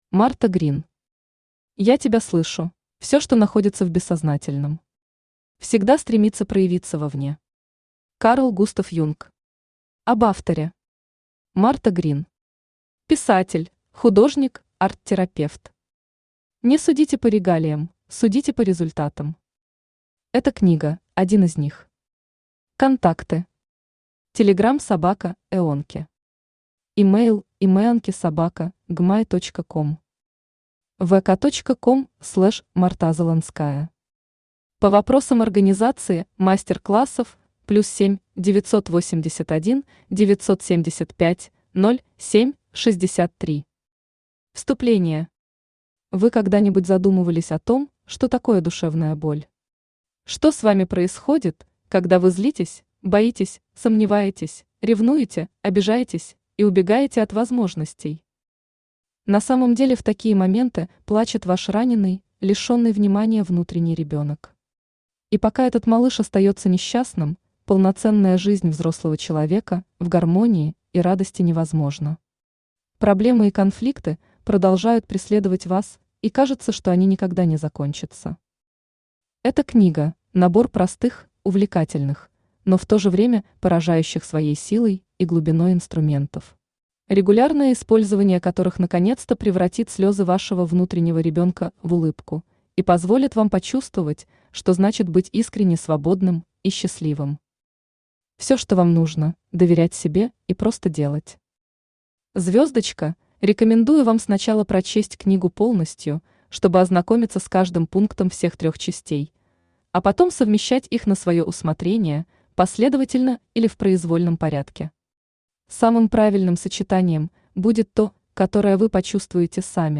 Аудиокнига Я Тебя Слышу | Библиотека аудиокниг
Aудиокнига Я Тебя Слышу Автор Марта Грин Читает аудиокнигу Авточтец ЛитРес.